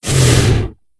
c_hakkar_hit1.wav